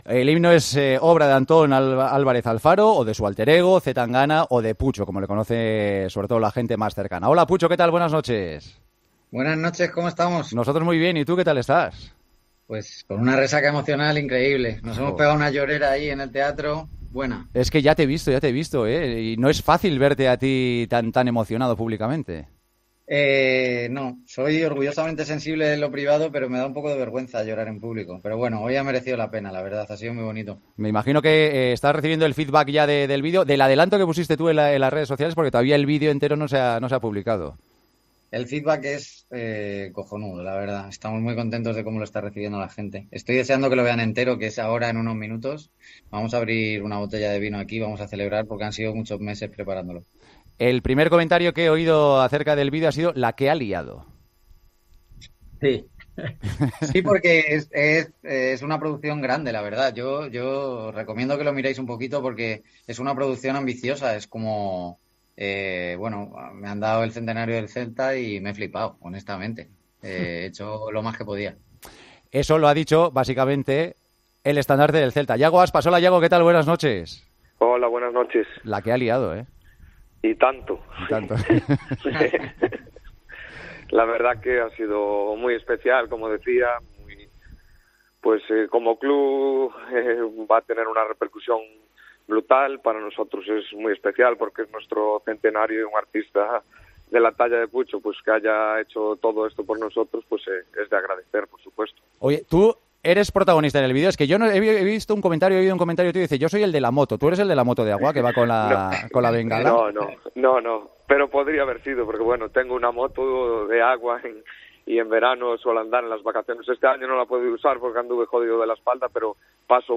El artista madrileño fue entrevistado con Iago Aspas en El Partidazo de COPE minutos antes del estreno del himno del centenario: "Ser del Celta en Madrid es una batalla".